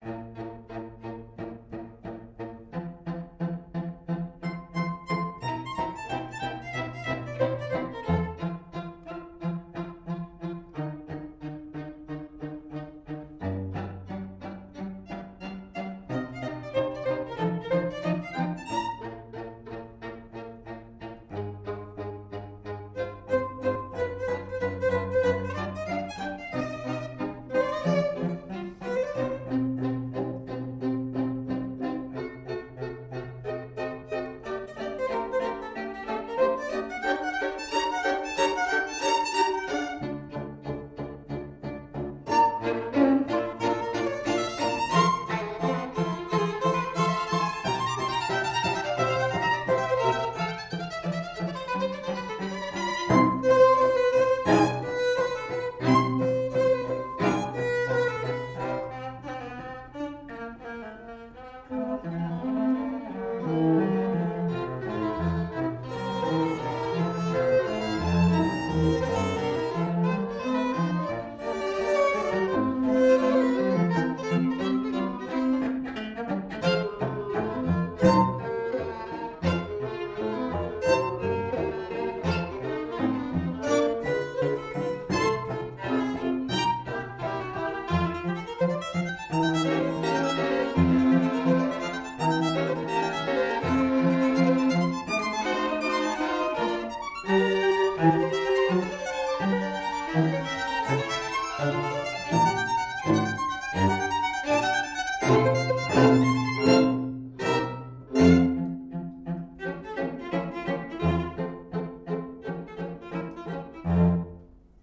Soundbite 4th Movt